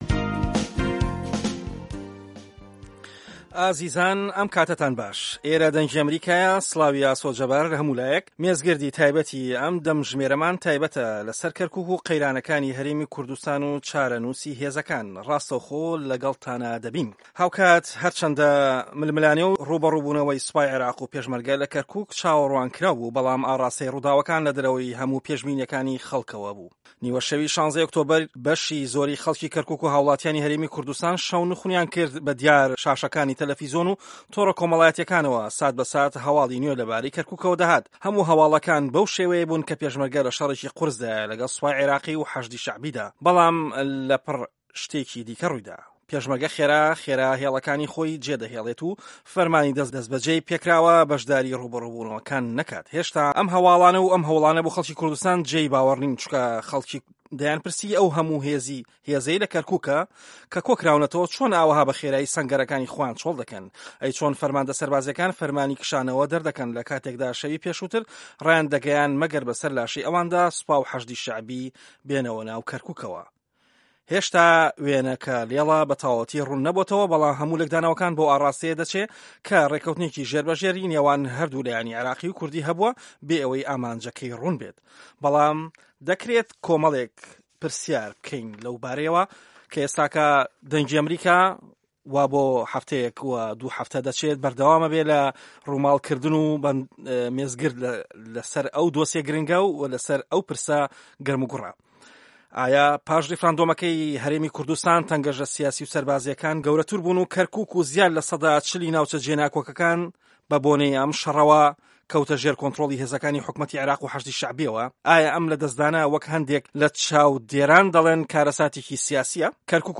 مێزگرد: که‌رکوک و قه‌یرانه‌کان له‌ گه‌ڵ به‌غدا